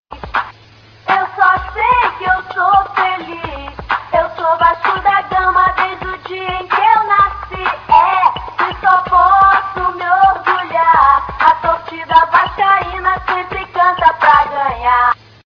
uma paródia